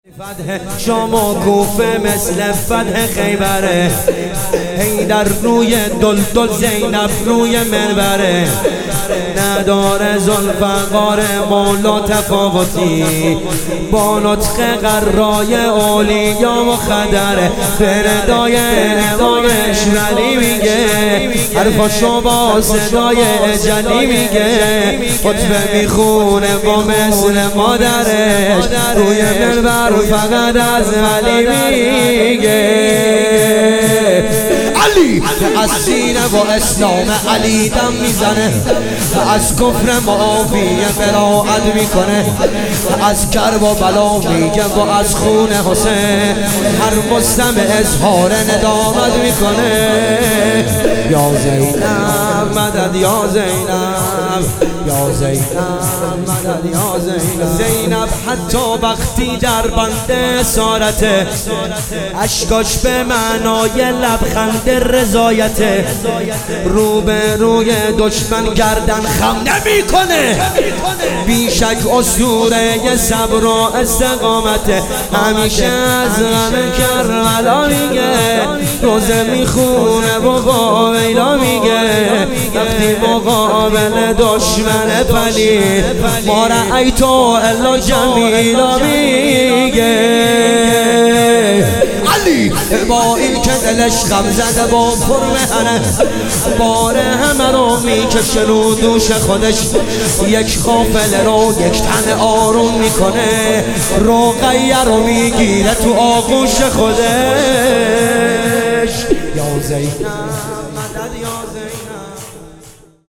چاووش محرم